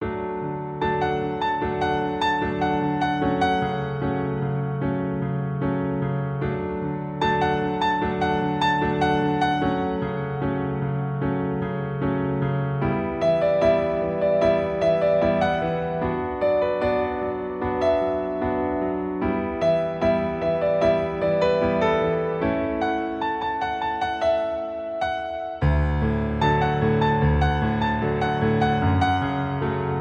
• 🎹 Instrument: Piano Solo
• 🎼 Key: D Major
• 🎶 Genre: Pop
with this expressive piano solo arrangement.
this powerful pop ballad